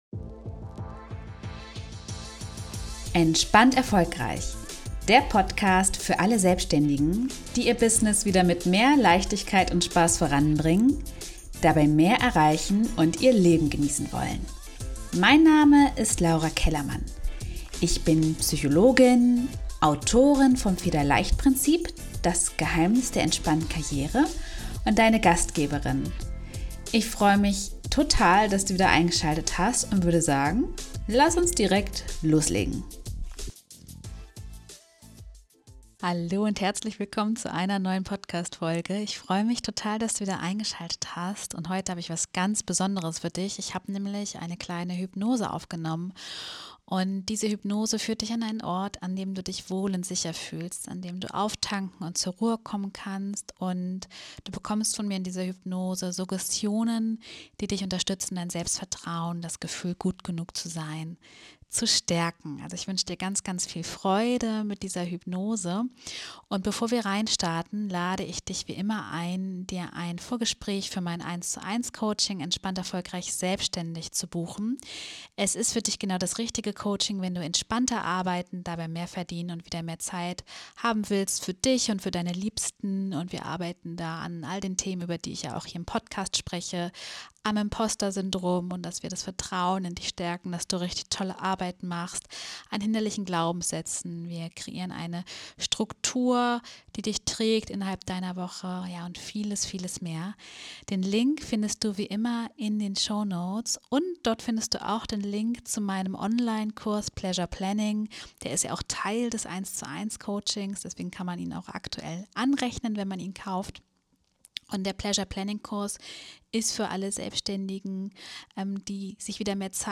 Ich habe dir eine Hypnose aufgenommen, um dein Vertrauen in dich & dein Können zu stärken! Ich führe dich an einen Ort, an dem du dich wohl und sicher fühlst, auftanken kannst.
Podcast_Hypnose_Selbstvertrauen.m4a